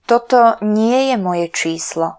Slovak voice announciation